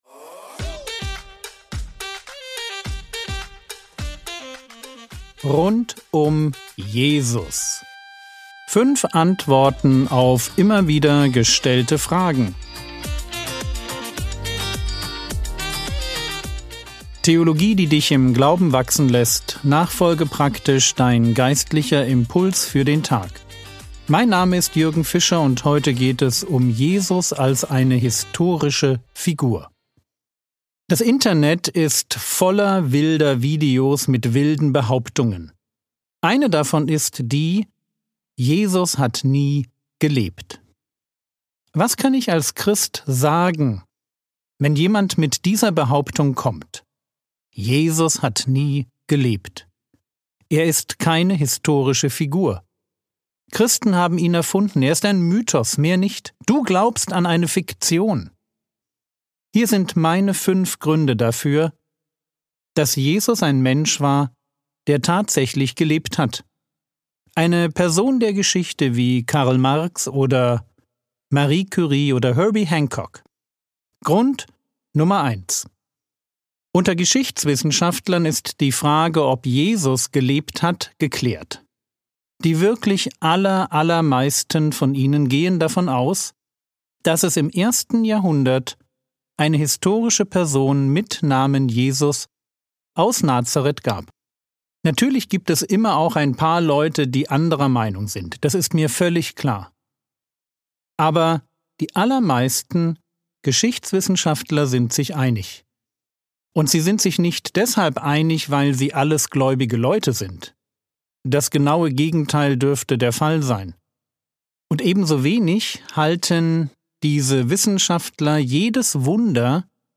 Rund um Jesus (1/5) ~ Frogwords Mini-Predigt Podcast